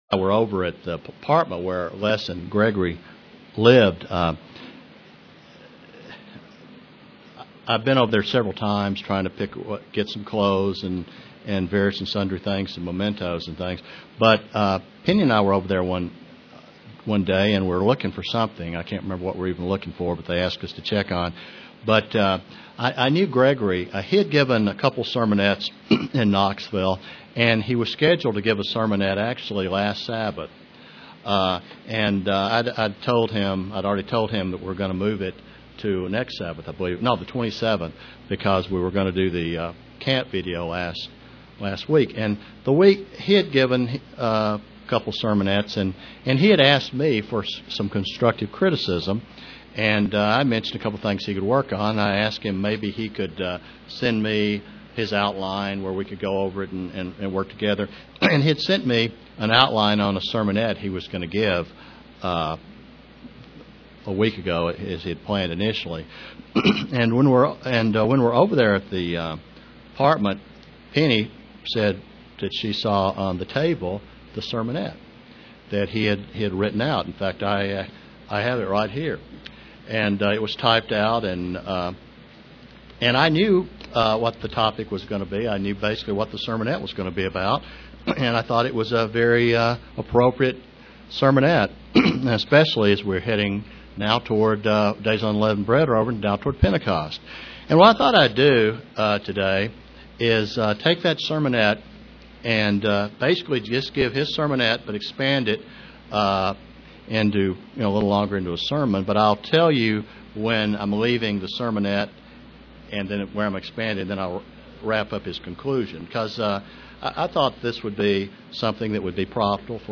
Print A Christian must know and believe the OT as well as the NT UCG Sermon Studying the bible?